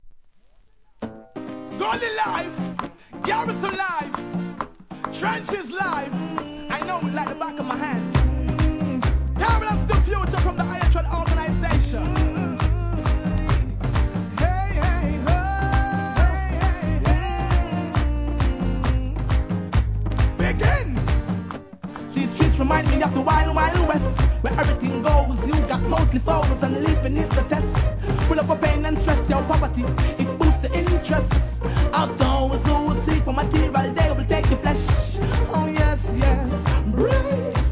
REGGAE
アコギが効いたアッパーリディム!